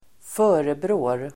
Uttal: [²f'ö:rebrå:r]